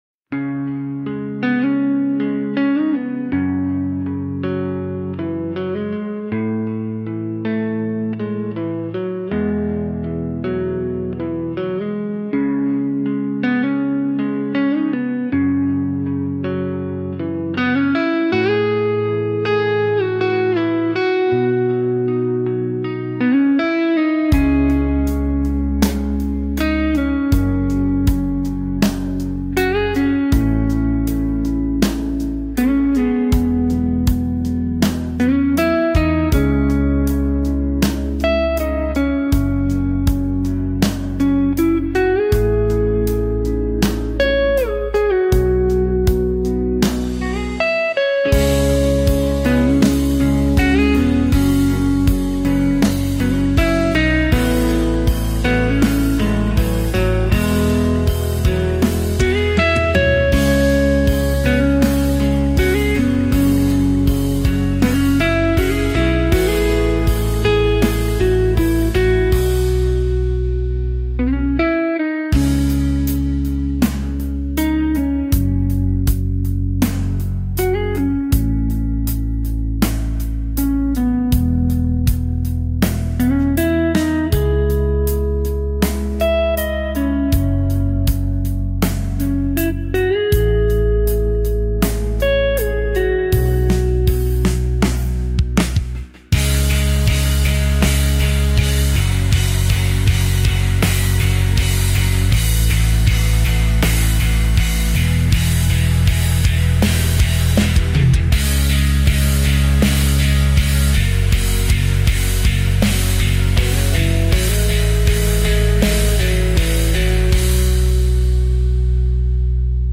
Instrumental
AI Generated Music